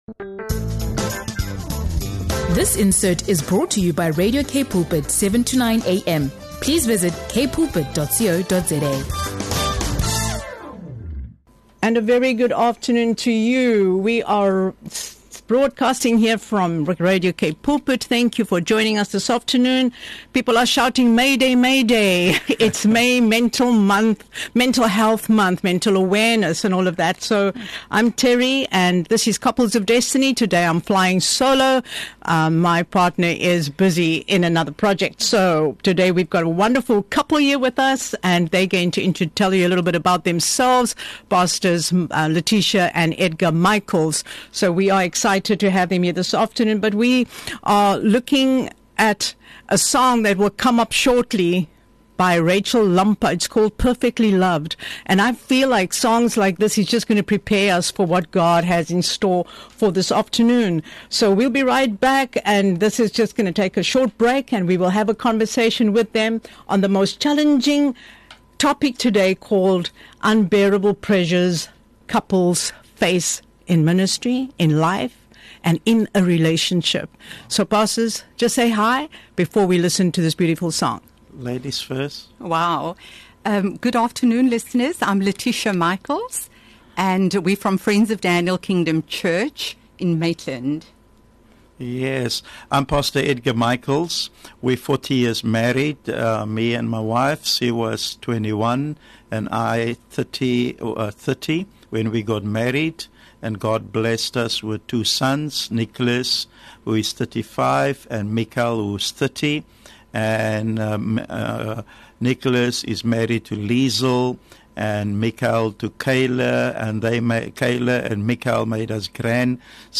Tune in for a Spirit-led conversation filled with revelation, real talk, and restoration.